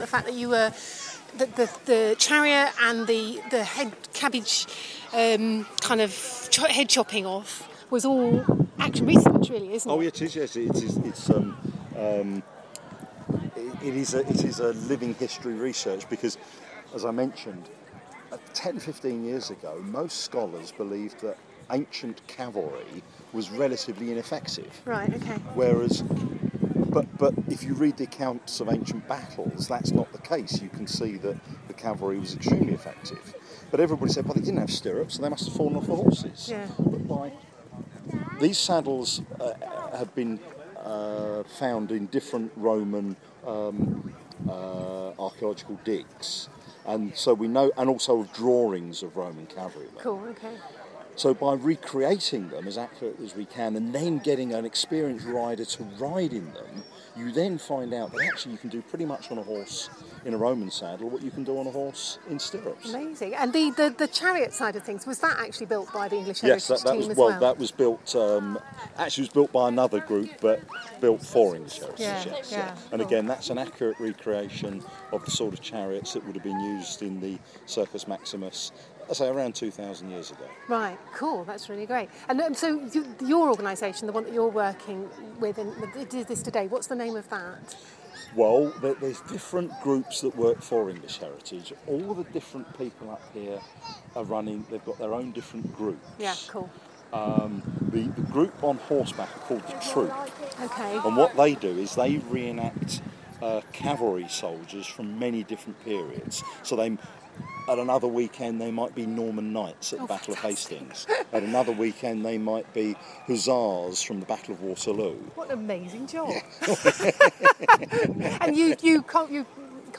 Interview with EH consultant